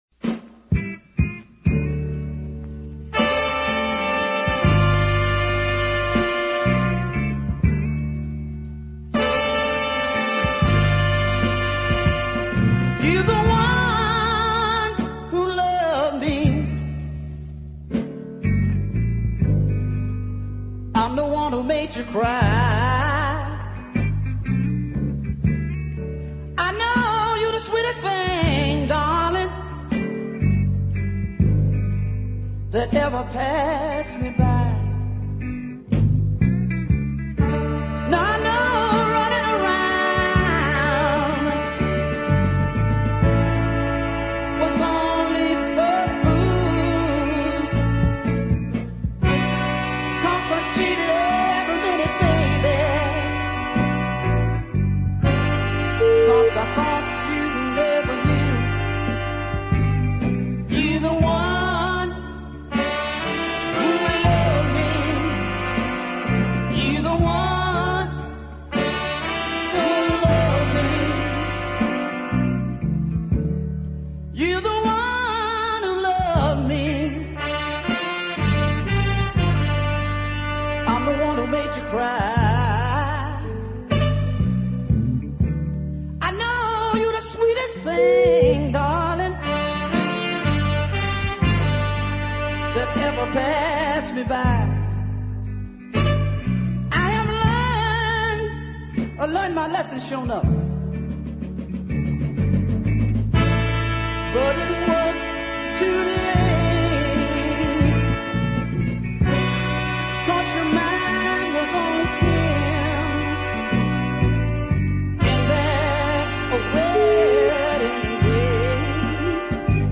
classic deep soul duet